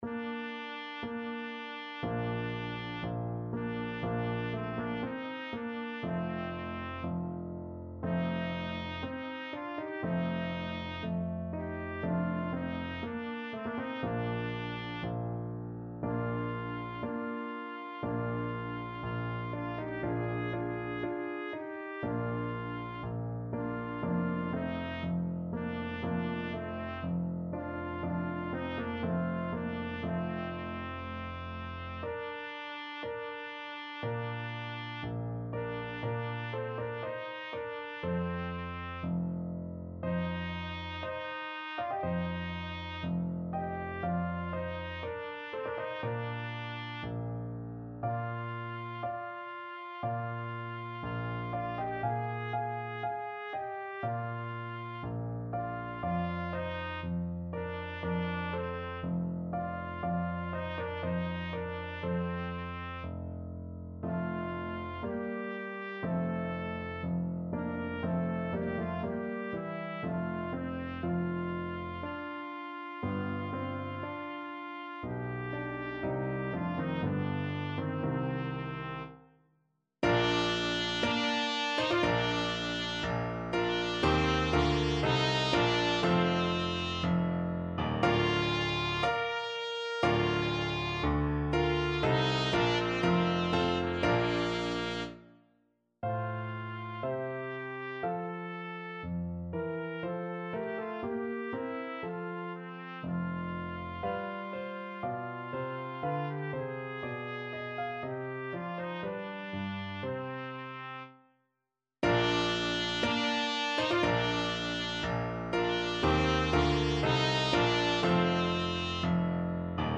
Classical Handel, George Frideric Funeral March from Saul Trumpet version
Trumpet
4/4 (View more 4/4 Music)
Bb major (Sounding Pitch) C major (Trumpet in Bb) (View more Bb major Music for Trumpet )
Slow =c.60
Classical (View more Classical Trumpet Music)